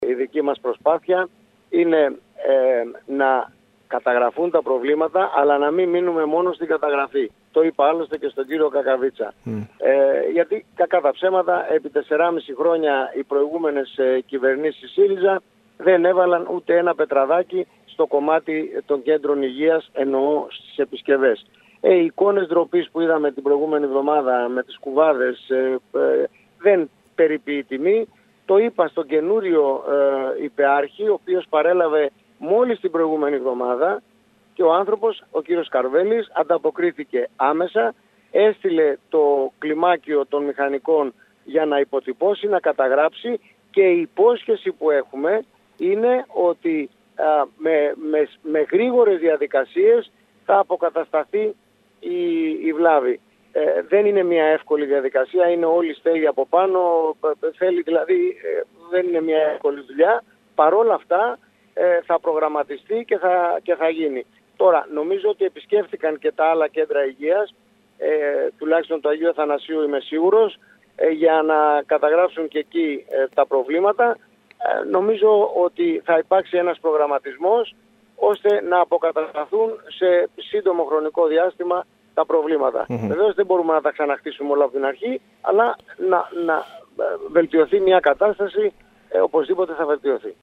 Ο κ. Γκίκας μιλώντας στο σταθμό μας, αναφέρθηκε μεταξύ άλλων και στην παρέμβαση που έκανε προκειμένου η 6η ΥΠΕ να επιληφθεί της συντήρησης που θα πρέπει να υπάρξει στις κτηριακές δομές των Κέντρων Υγείας της Κέρκυρας. Αφορμή υπήρξαν οι πρόσφατες εικόνες που παρουσίασε το Κ.Υ. Αγίου Μάρκου το οποίο πλημμύρισε κατά τη διάρκεια της τελευταίας νεροποντής.